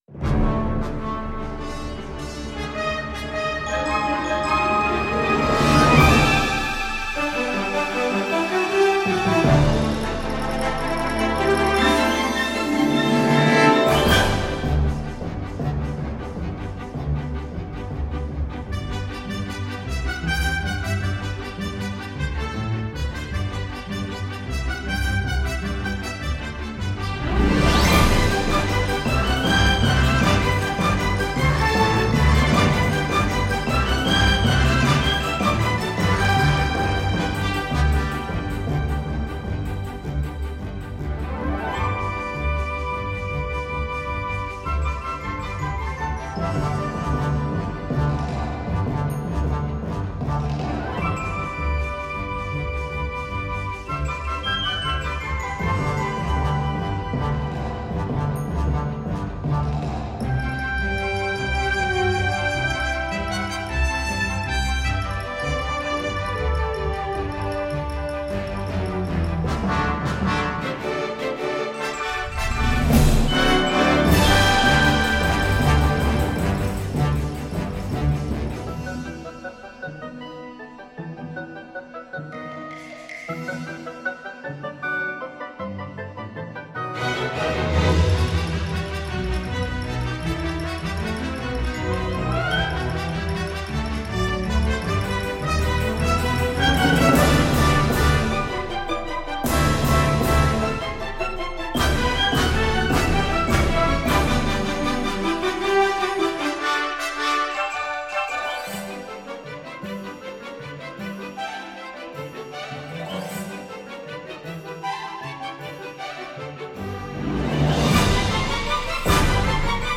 Berlin Brass EXP B Muted Brass 1.0
旗舰铜管乐器和合奏
在每一场动态中，柏林铜管乐乐团都能提供平衡、圆润的声音，无缝融入您的管弦乐制作中。
自适应连奏对播放速度做出反应，以确保快速运行和富有表现力的旋律的正确声音。
多种启动选项为了增加控制程度，请在软攻击、即时启动和重音启动之间进行选择。